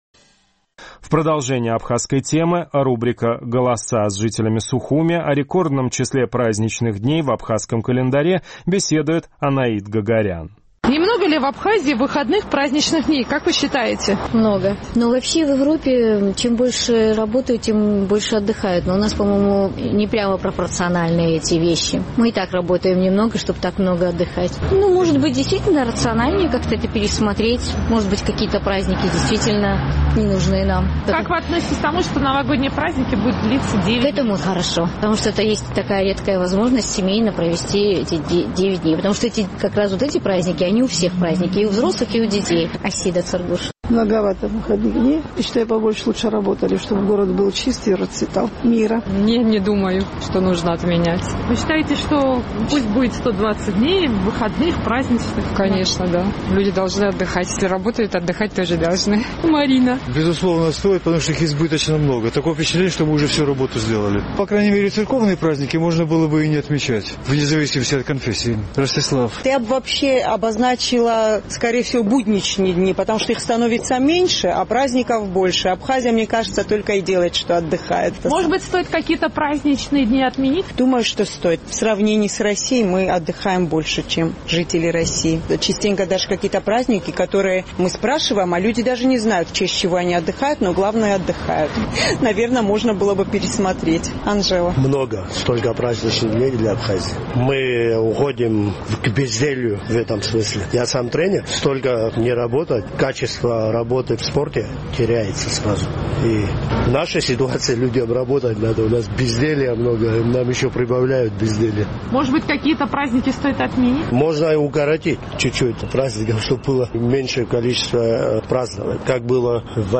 Наш сухумский корреспондент поинтересовалась мнением жителей абхазской столицы по поводу большого количества праздничных выходных дней.